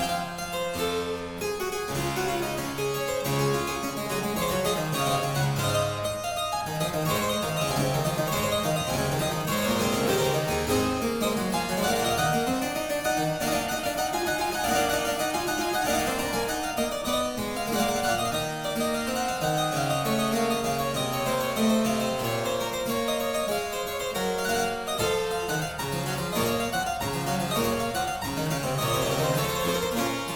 clavecin